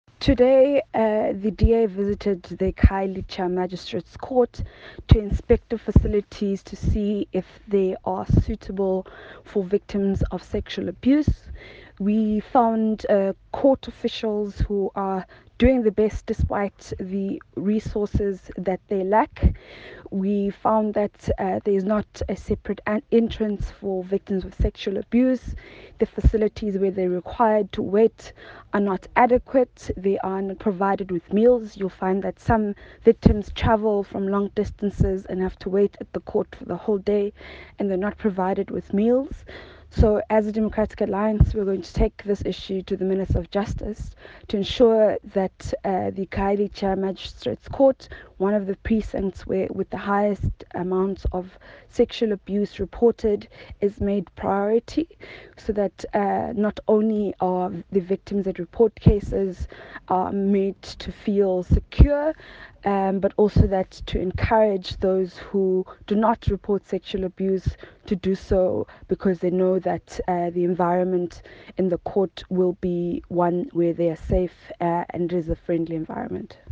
Note to Editors: Please find attached a soundbite in
English by DA Spokesperson on Corruption, Phumzile Van Damme MP.